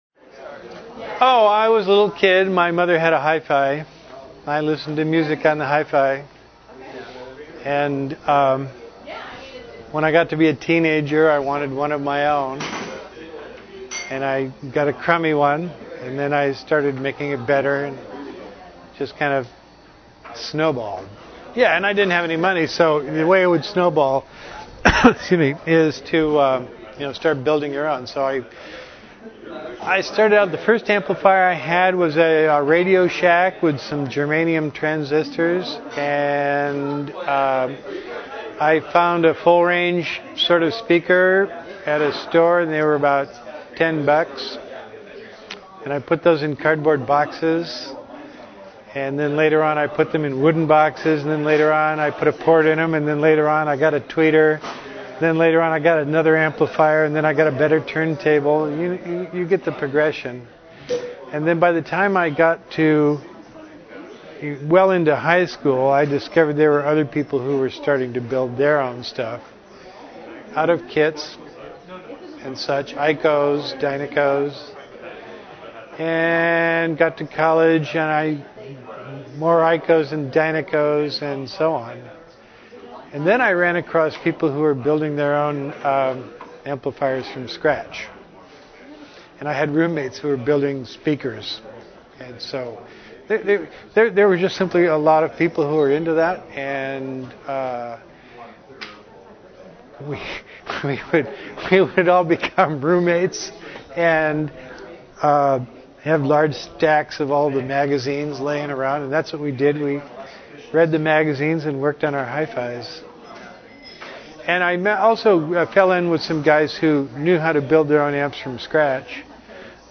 NP-Interview.mp3